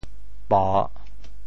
报（報） 部首拼音 部首 土 总笔划 12 部外笔划 9 普通话 bào 潮州发音 潮州 bo3 文 中文解释 报 <动> (会意。
po3.mp3